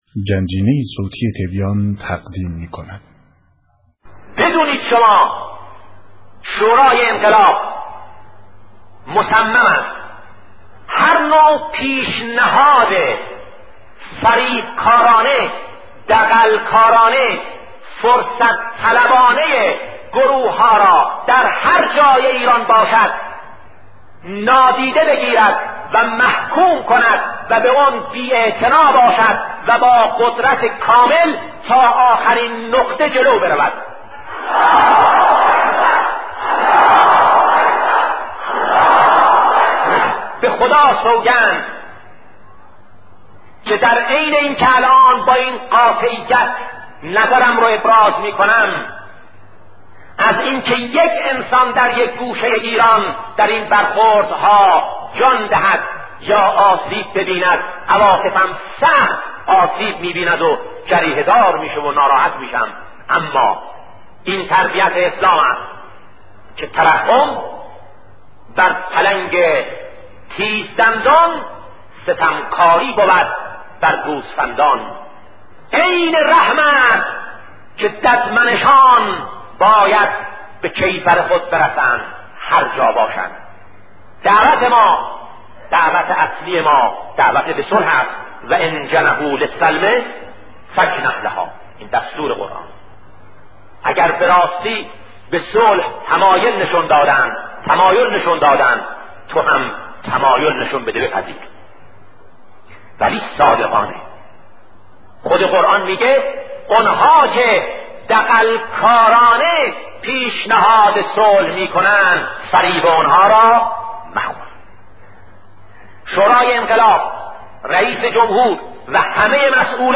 سخن شهید بهشتی-درباره استاد مطهری-بخش‌دوم